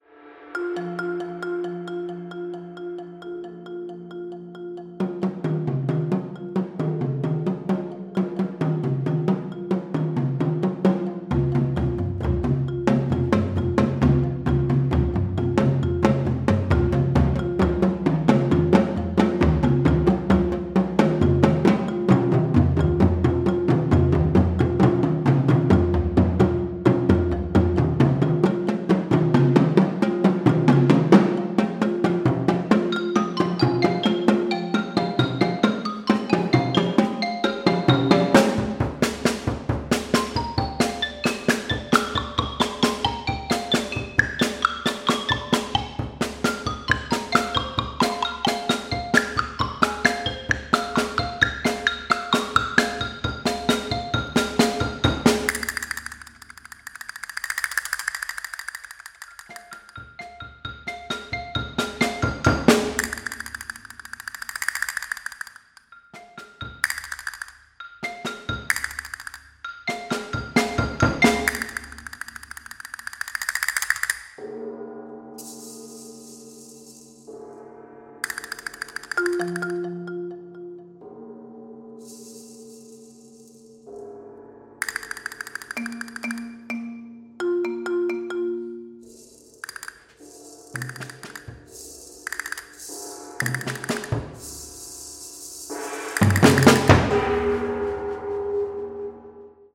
documenting never before heard chamber works